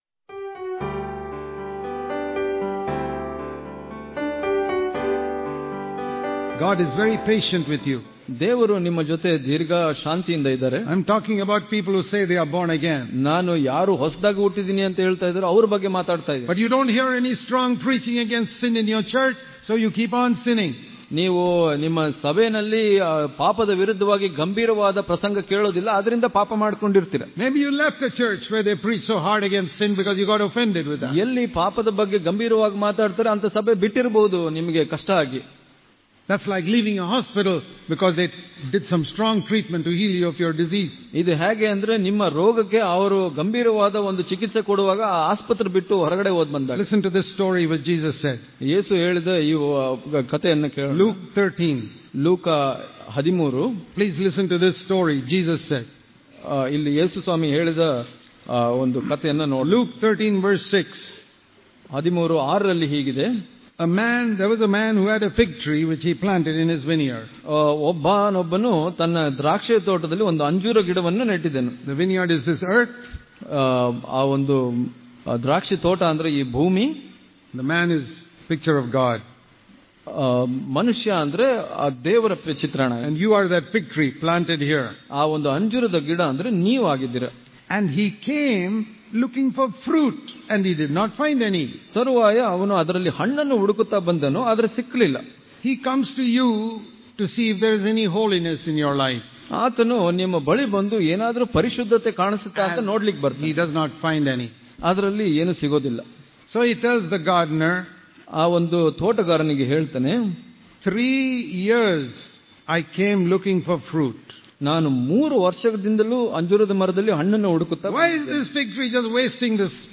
ಇಂದಿನ ಧ್ಯಾನ
Daily Devotions